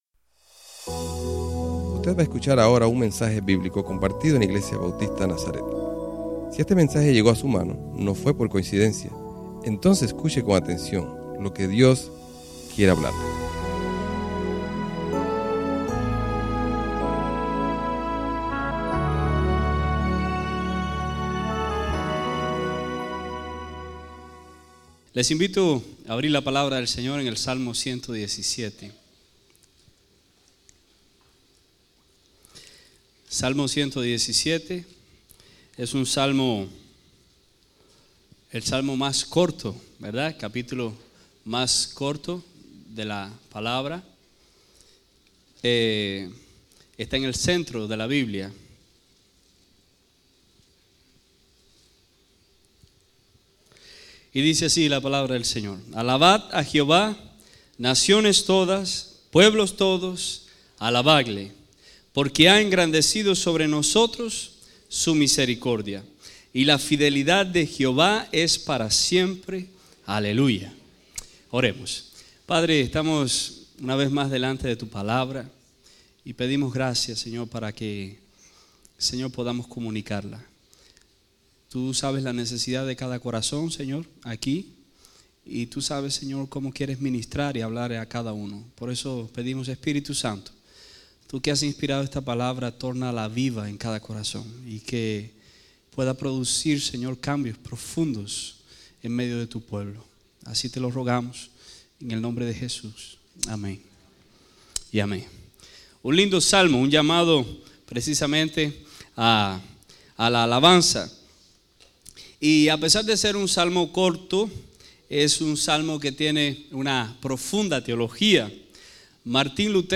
Servicio Dominical